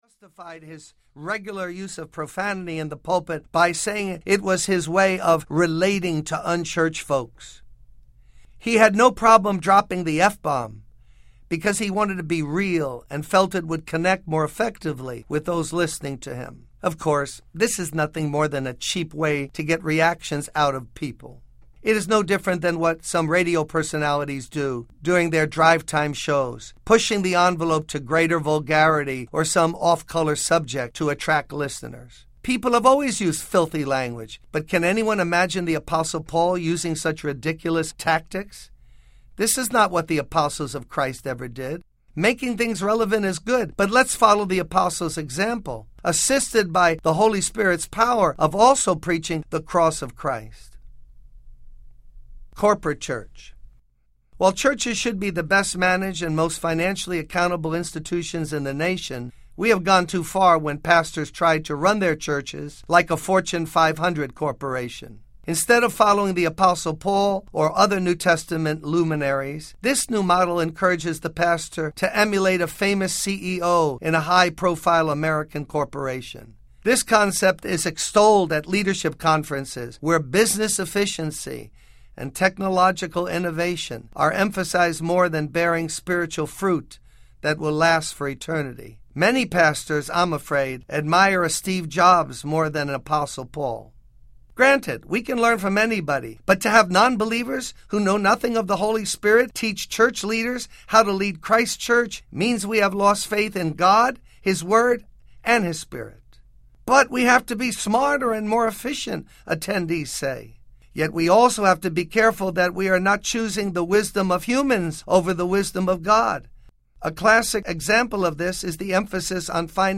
Storm Audiobook